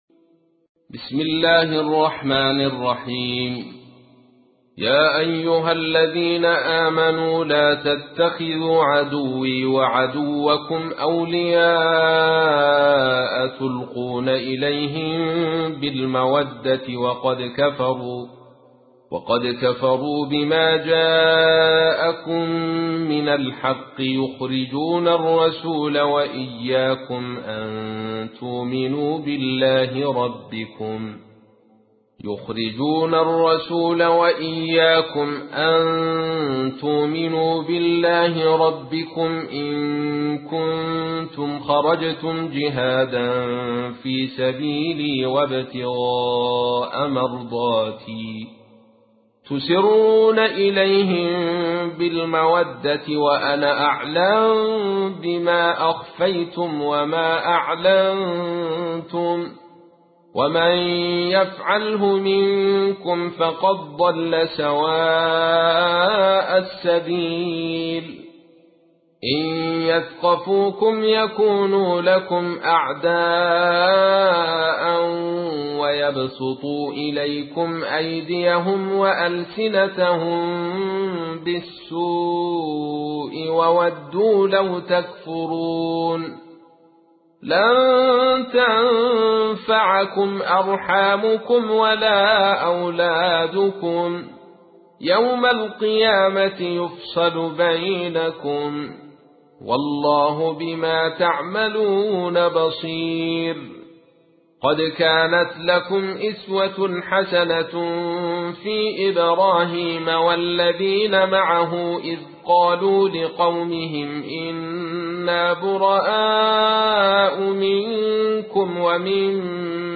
تحميل : 60. سورة الممتحنة / القارئ عبد الرشيد صوفي / القرآن الكريم / موقع يا حسين